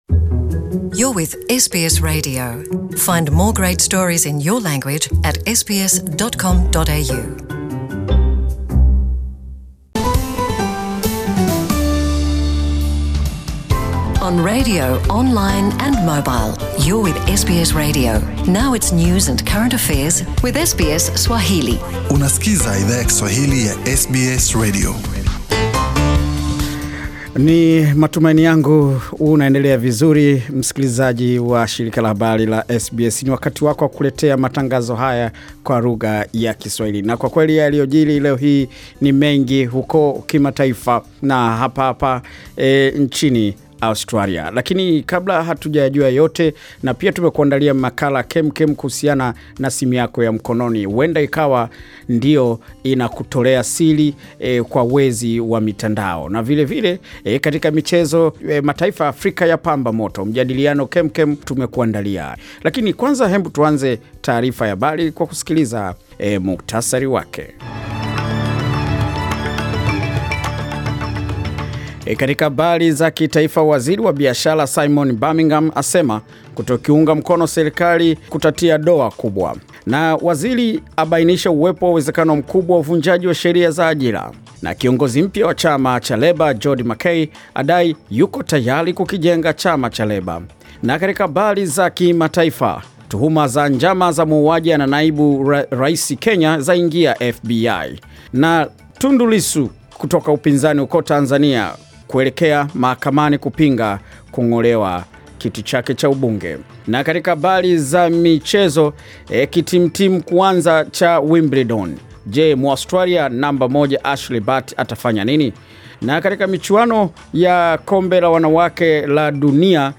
Taarifa ya Habari